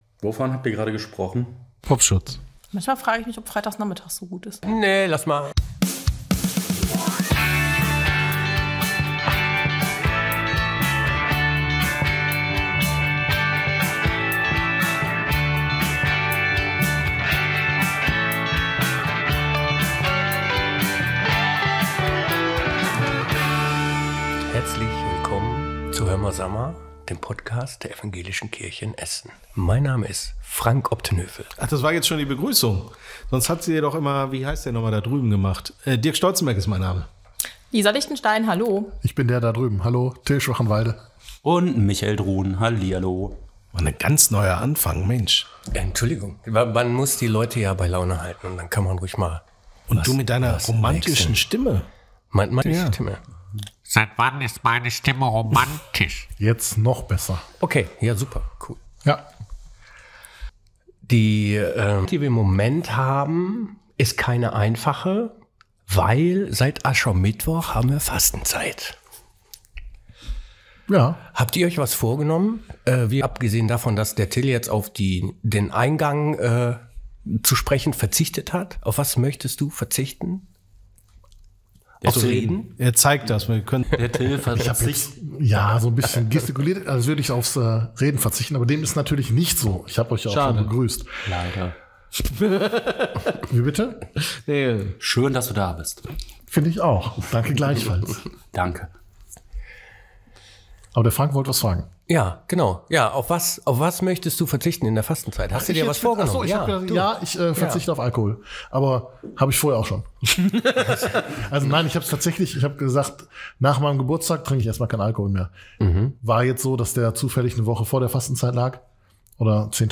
Unser "Hömma Samma"-Team hat sich da Gefanken drüber gemacht und die fünf erzählen, wie sie mit diesem Thema umgehen.